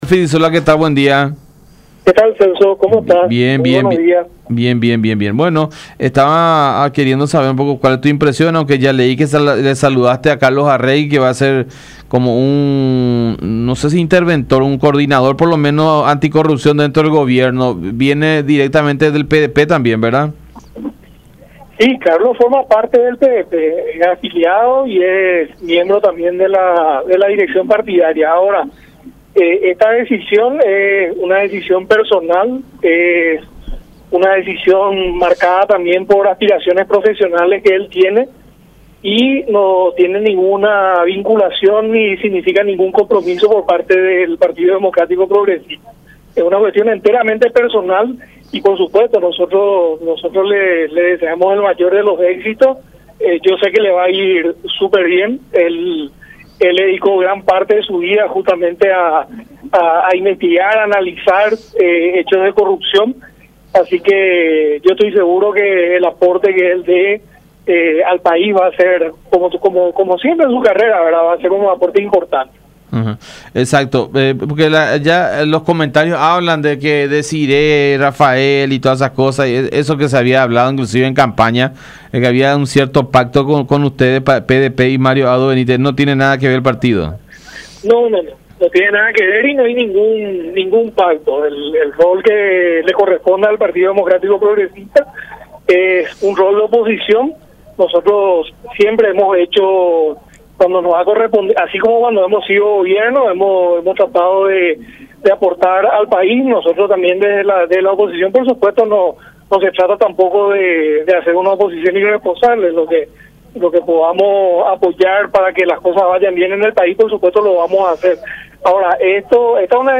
“Es una aspiración profesional y personal que él tiene y no significa ningún compromiso por parte del Partido Democrático Progresista”, expuso Filizzola en comunicación con La Unión, confiando en que el trabajo del exfiscal “será de muy importante para el país”.